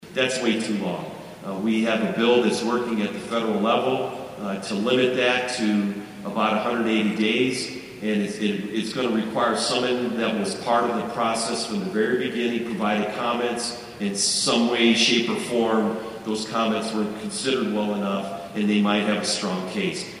spoke to a gathering at Tuesday’s conference in Manhattan